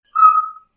bip_01.wav